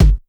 Kick_58.wav